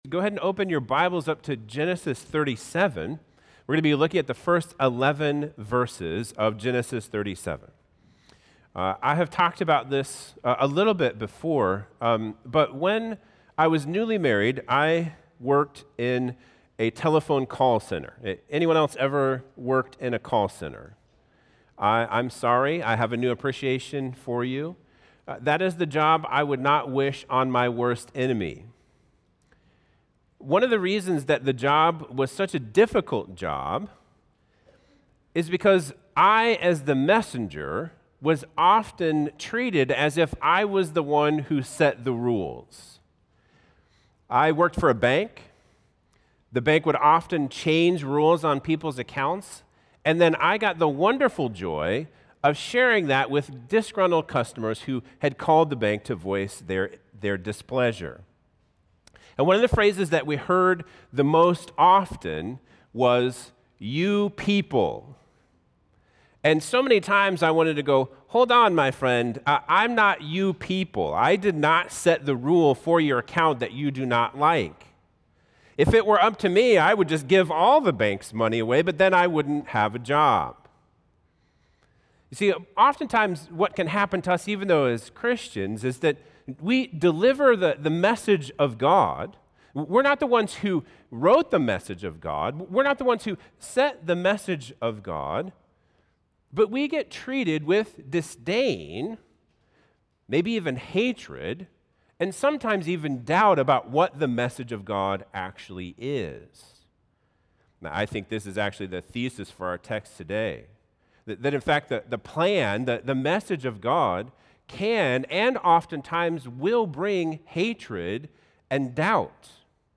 Sermons | CrossBridge Church